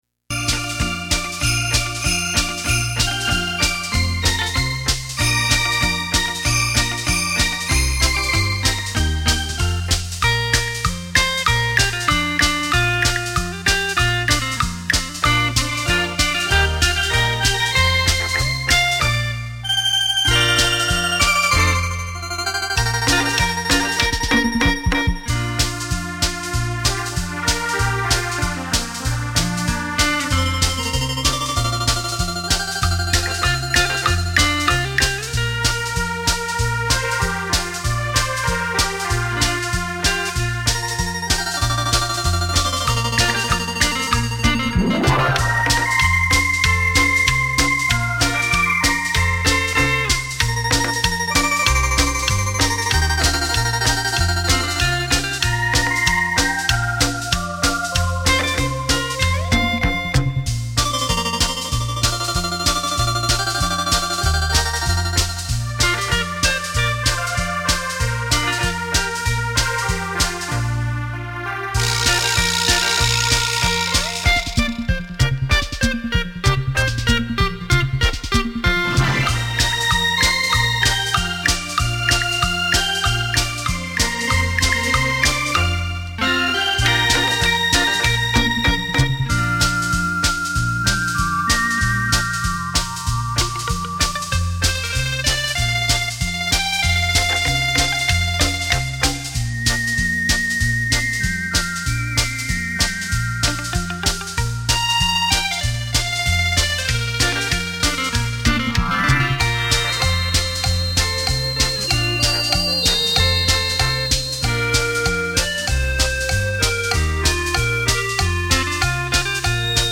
电子琴诠释经典 表现出另一种风情
经典的歌曲 全新的演绎 浪漫双电子琴带你回味往事
一听再听 百听不厌的名曲演奏